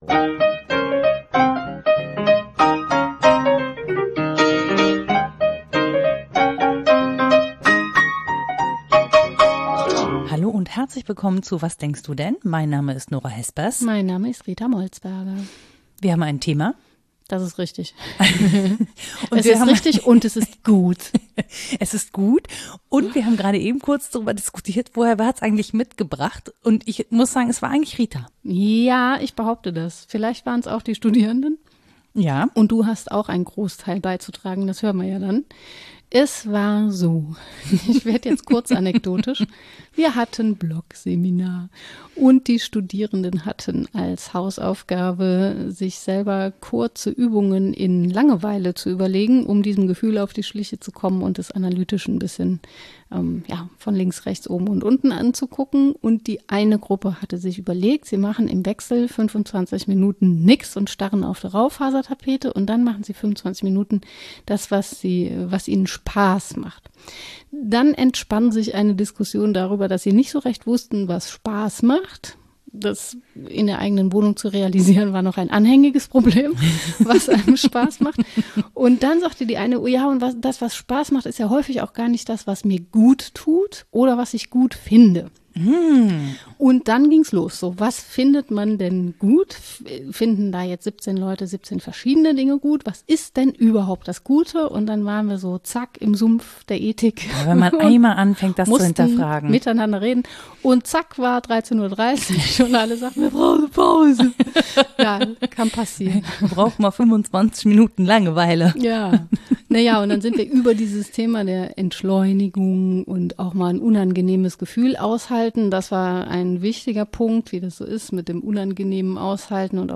**Was ist eigentlich das Gute? Und wie können wir uns selbst so in die Gesellschaft einbringen, dass mehr davon entsteht? Ein Gespräch über Hoffnung, Solidarität - und den Größenwahn, die Welt retten zu wollen.**